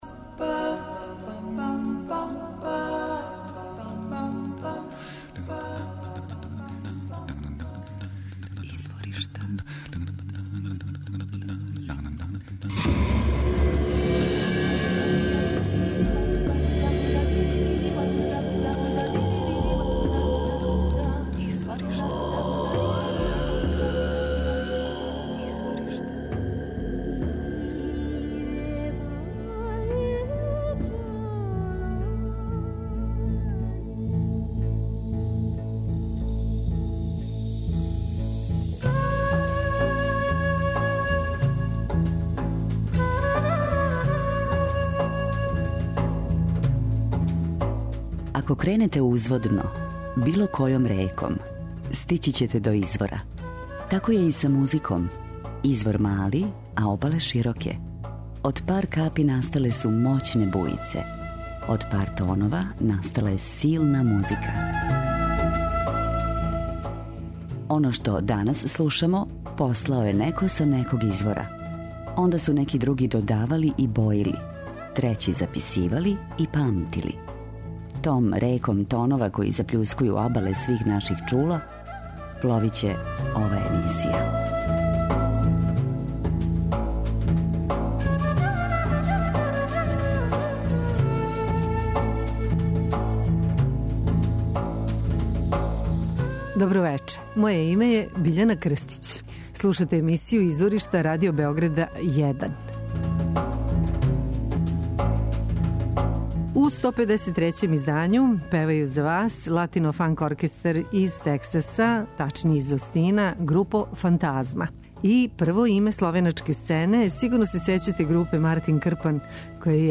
Велики је утицај циганске музике, blues-a и све је обојено "рокерском енергијом".